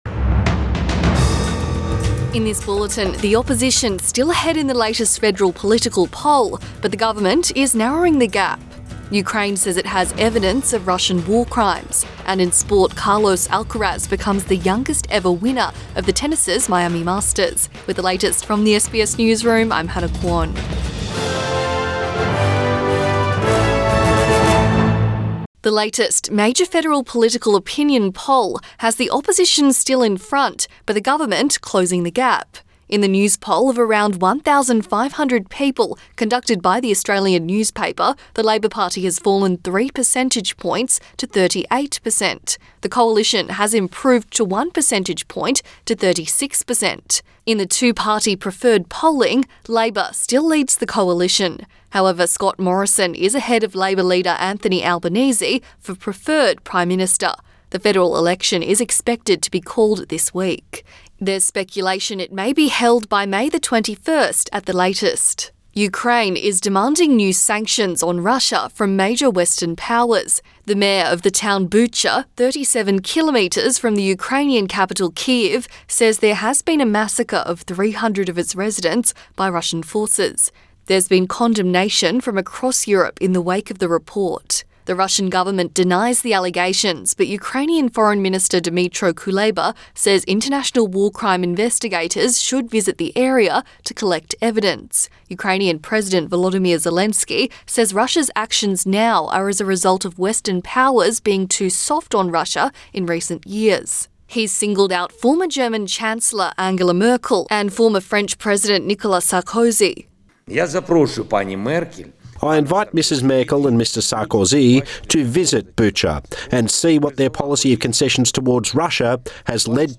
Midday bulletin 4 April 2022